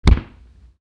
fall.wav